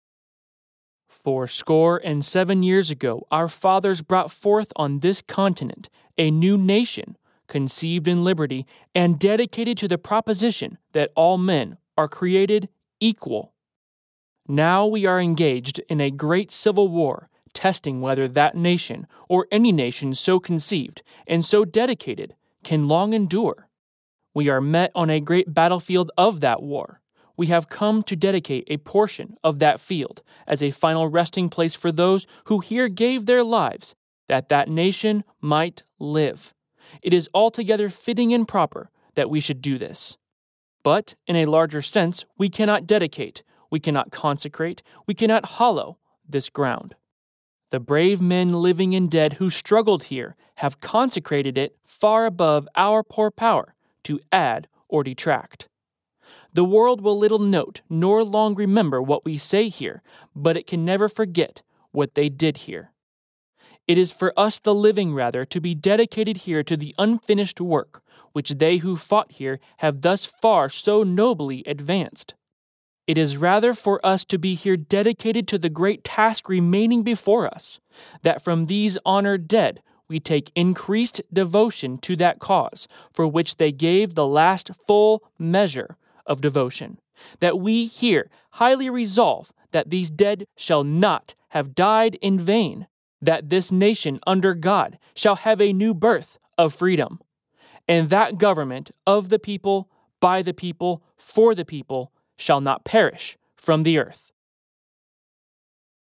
Audio Pro and Voice Artist
Historical Reading - Gettysburg Address